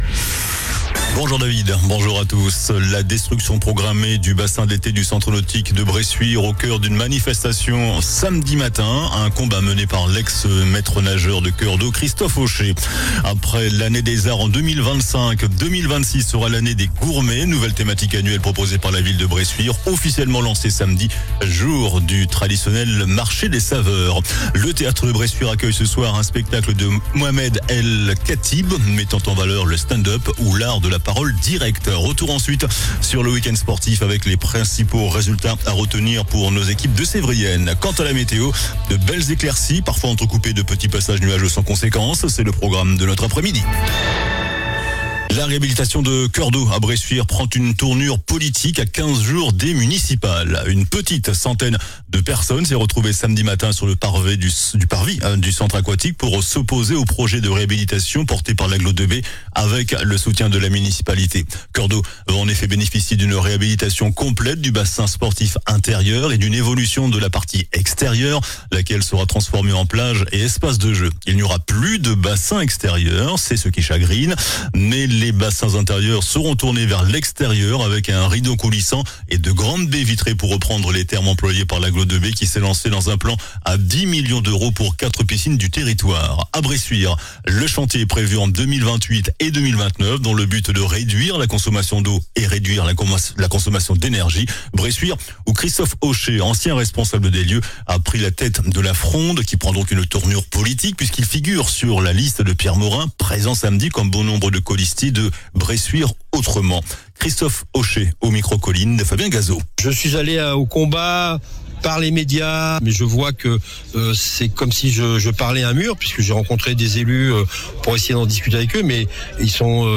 JOURNAL DU LUNDI 02 MARS ( MIDI )